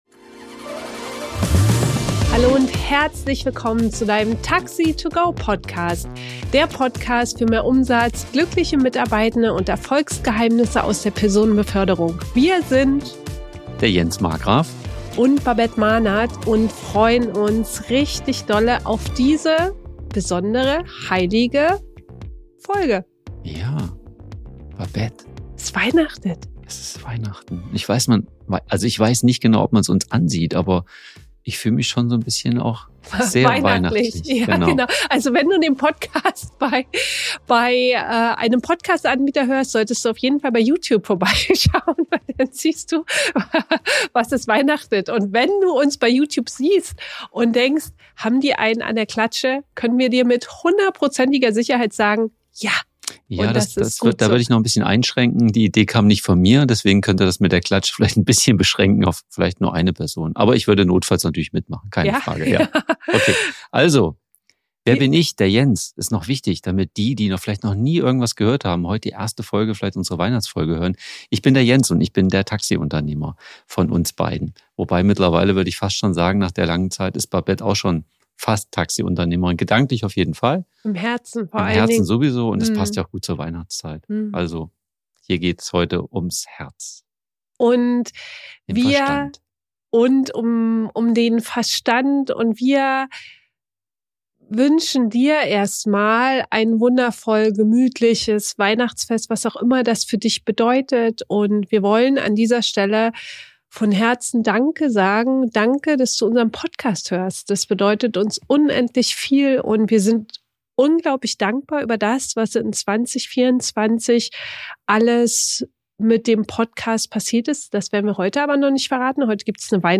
Los gehts! 4 spannende Fragen haben wir ihnen gestellt. Rausgekommen sind wundervolle Interviews mit tollen Taxi- und Mietwagenunternehmen:innen.